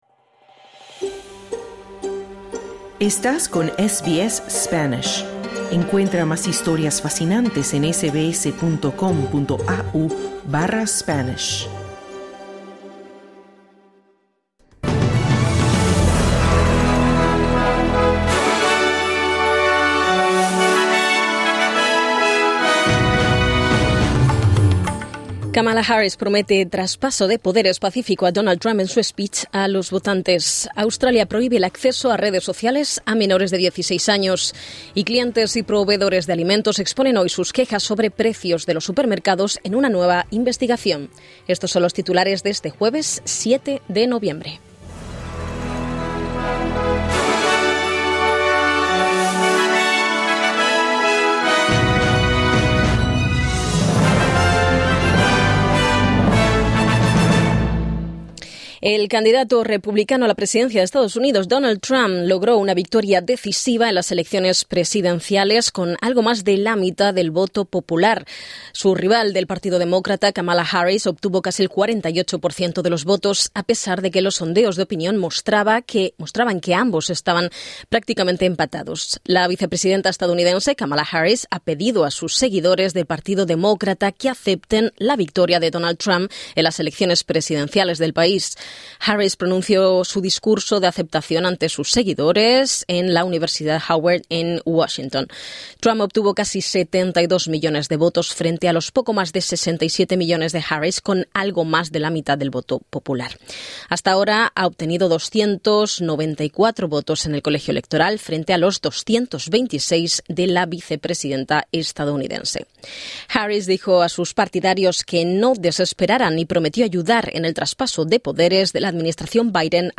Noticias SBS Spanish | 7 noviembre 2024